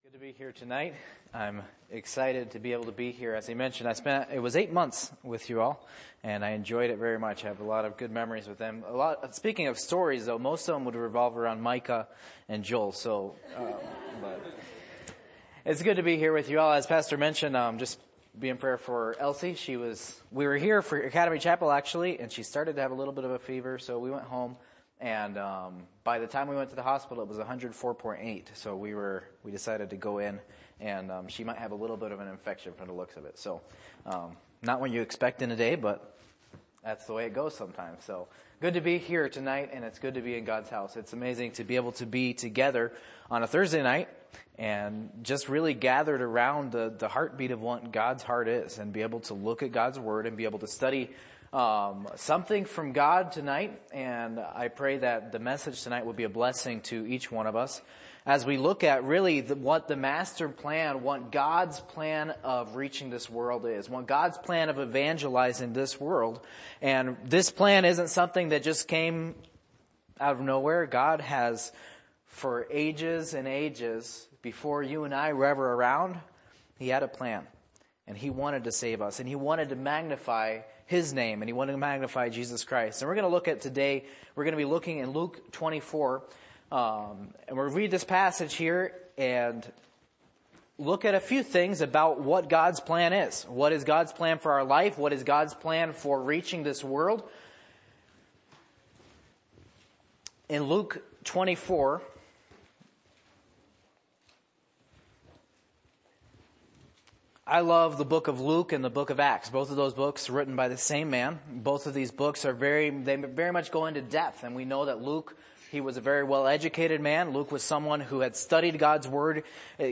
Passage: Luke 24:44-53 Service Type: Missions Conference